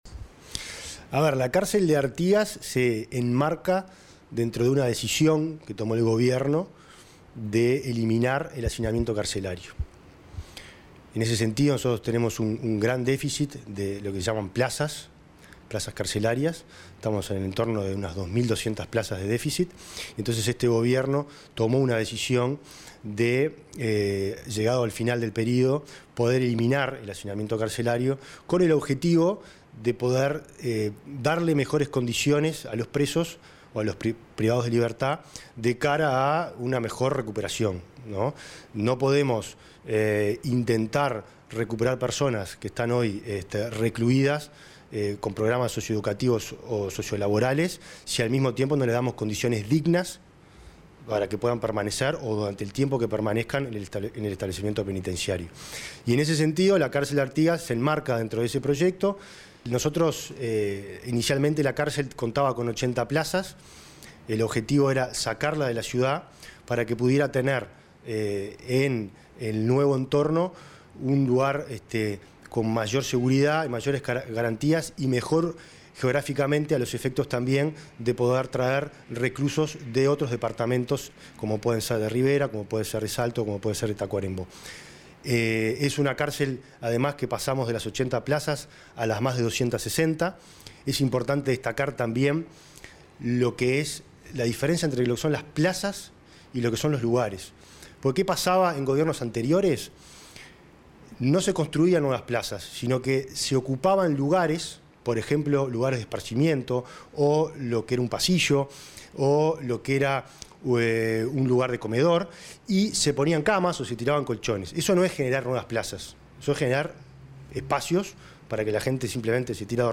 Entrevista al director general del Ministerio del Interior